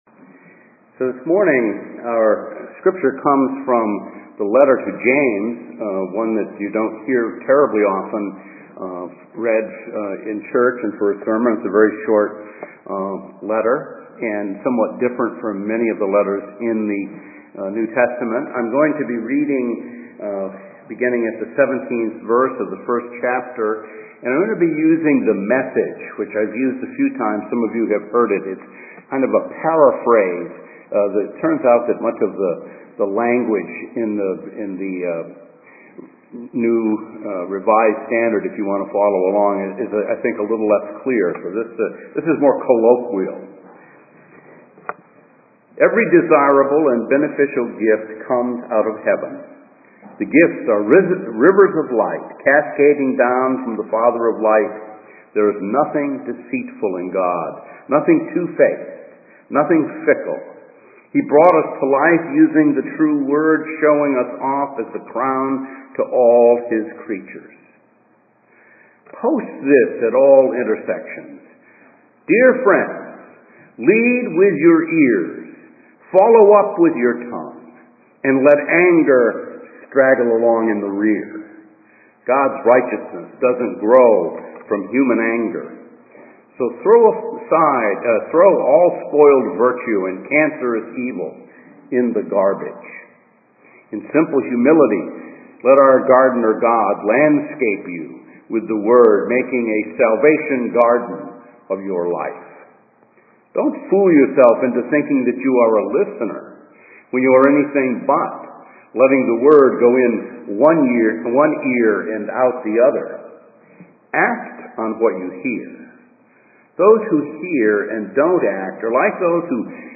A message from the series "Lectionary."